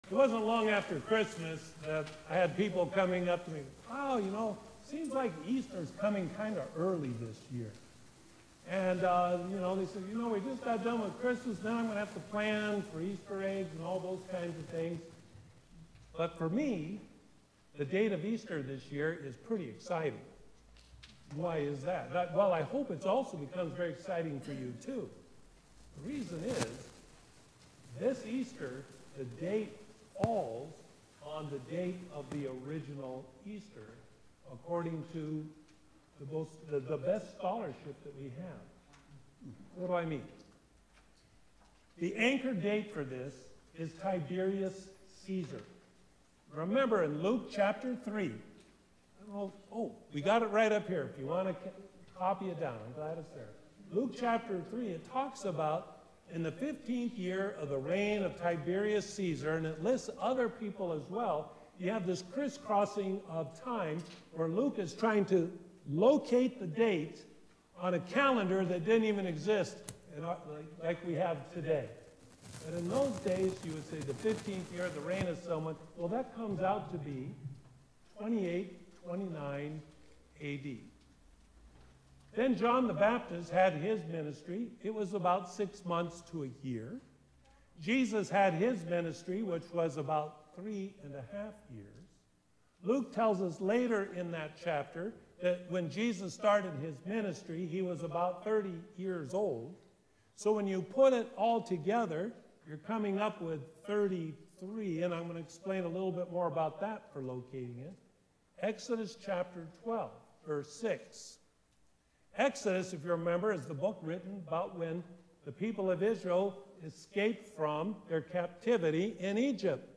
Sermon for The Transfiguration of Our Lord